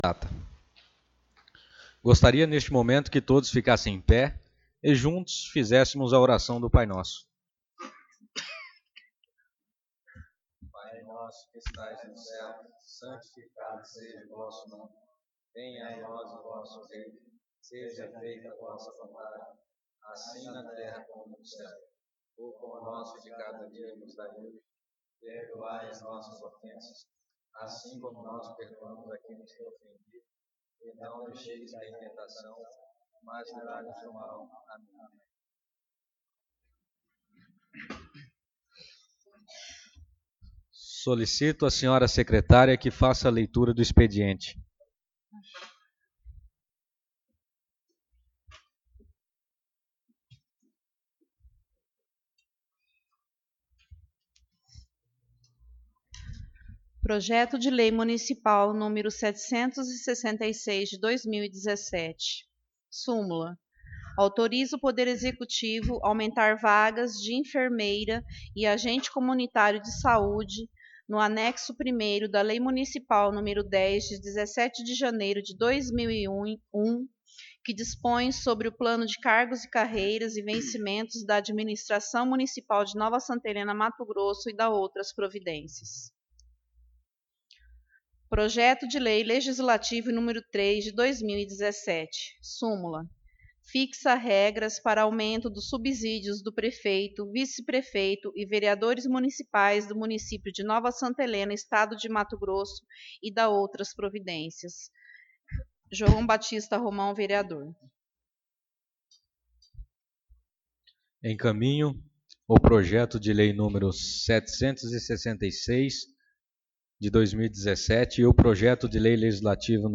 Sessão Ordinária 08/05/2017